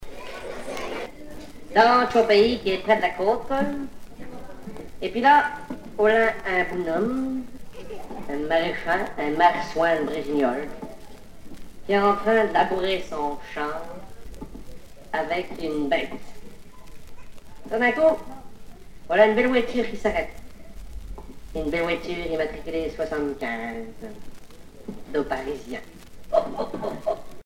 Genre sketch
Catégorie Récit